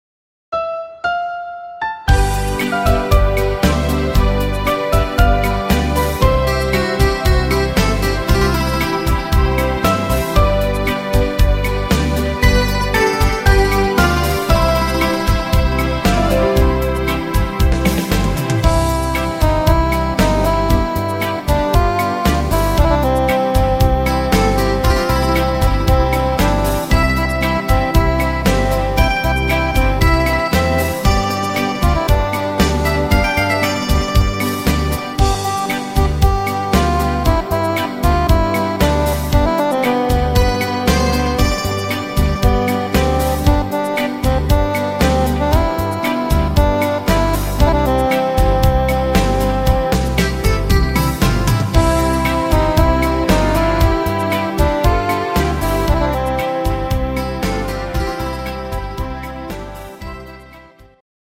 instr. Saxophon